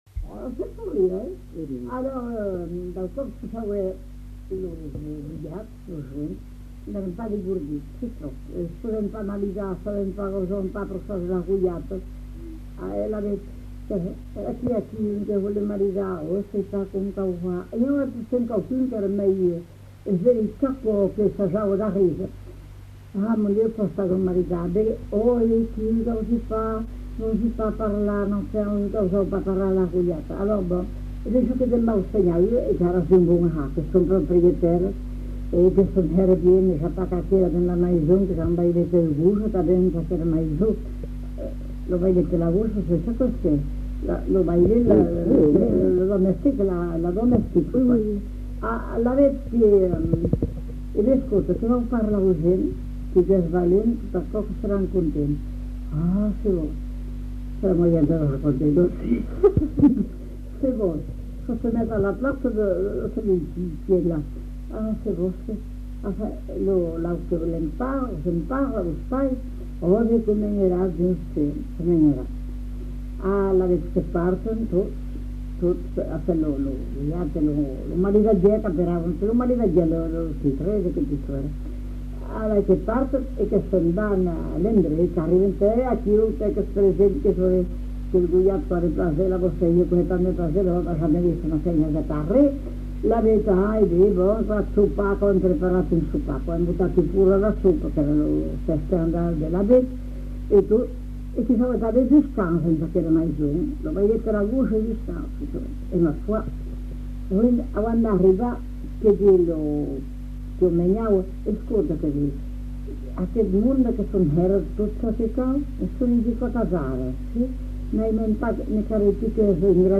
Aire culturelle : Marsan
Lieu : [sans lieu] ; Landes
Genre : conte-légende-récit
Effectif : 1
Type de voix : voix de femme
Production du son : parlé